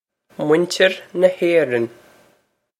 Pronunciation for how to say
mwin-chir na Hay-ron
This is an approximate phonetic pronunciation of the phrase.